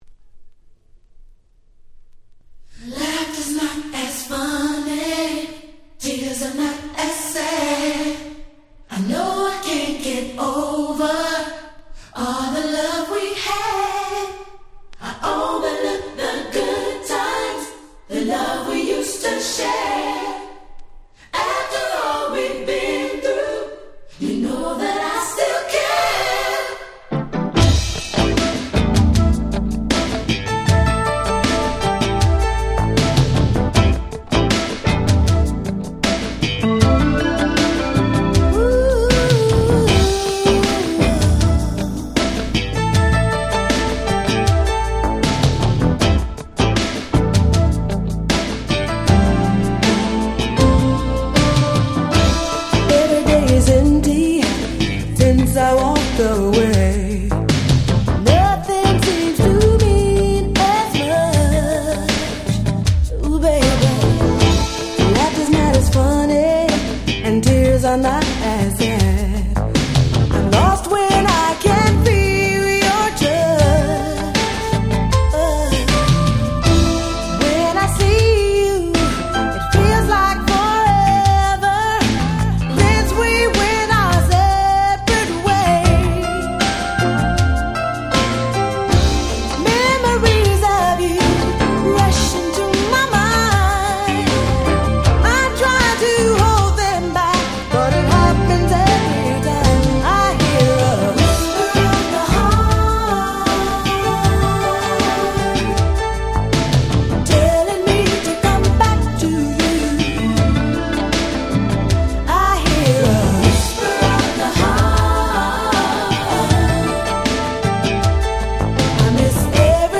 87' Nice R&B Album !!
80's R&B